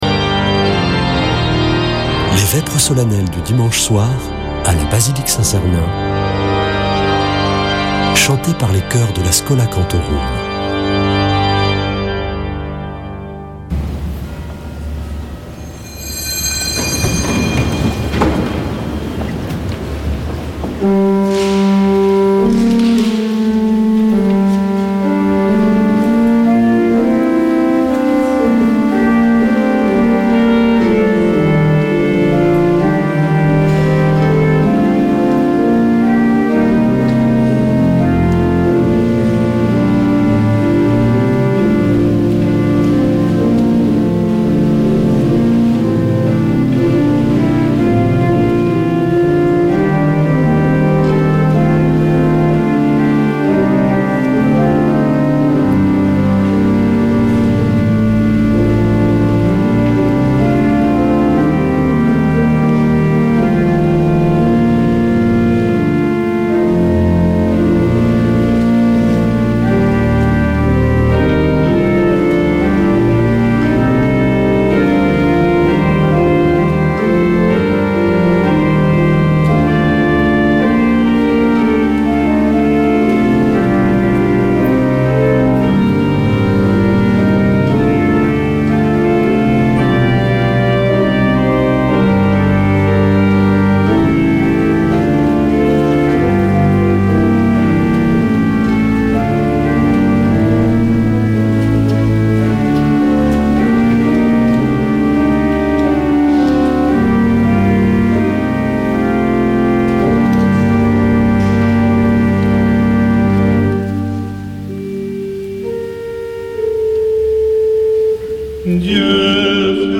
Vêpres de Saint Sernin du 29 oct.
Une émission présentée par Schola Saint Sernin Chanteurs